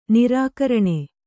♪ nirākaraṇe